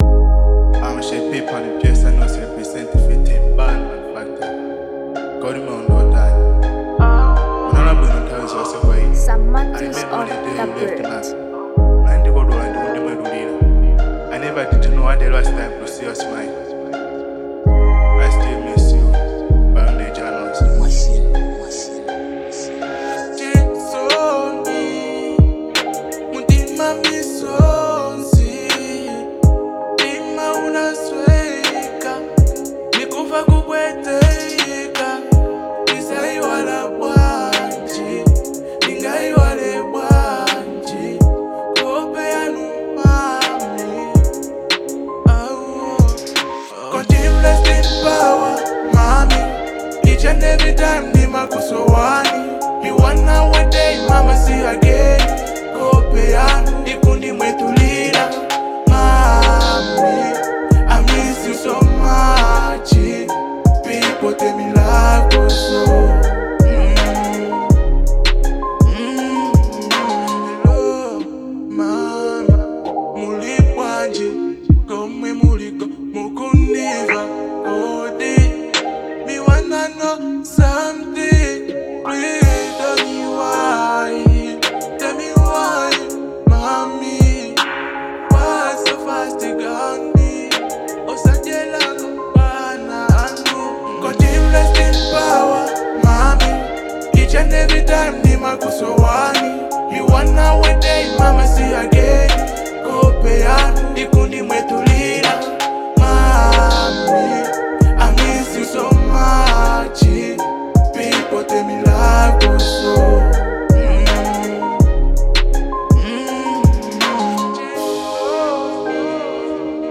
Afro-Dancehall